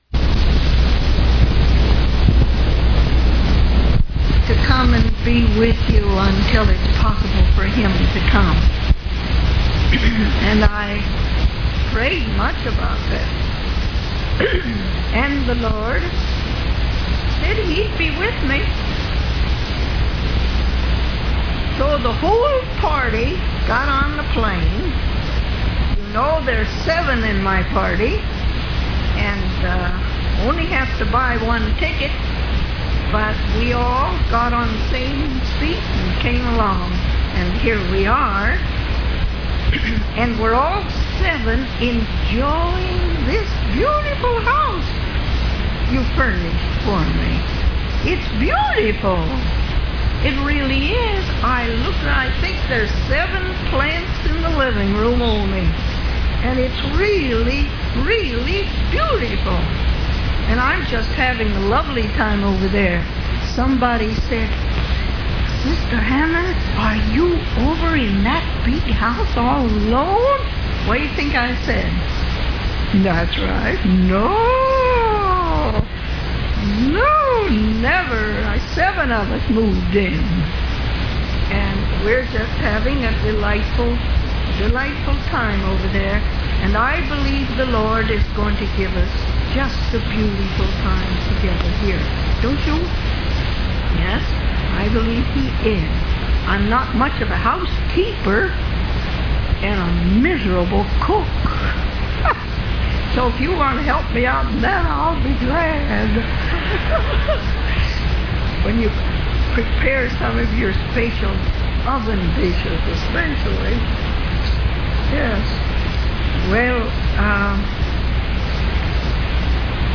In this sermon, the preacher emphasizes the importance of prayer and encourages the congregation to study and understand its significance.